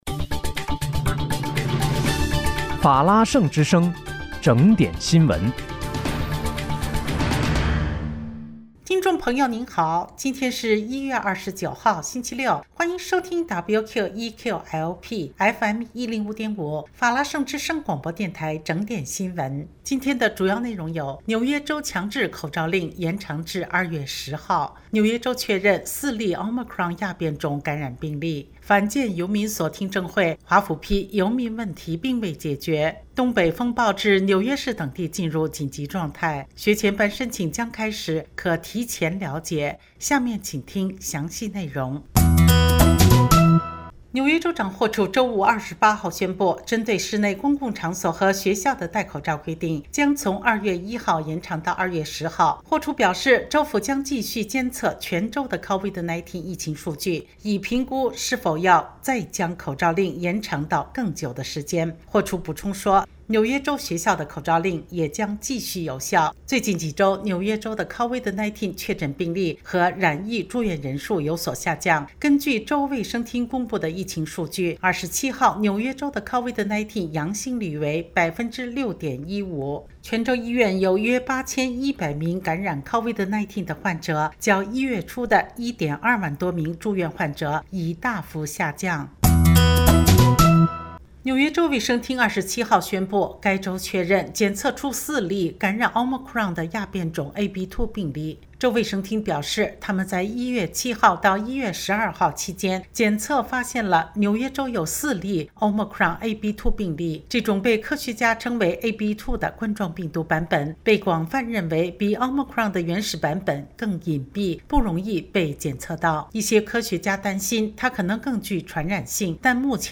1月29日（星期六）纽约整点新闻
听众朋友您好！今天是1月29号，星期六，欢迎收听WQEQ-LP FM105.5法拉盛之声广播电台整点新闻。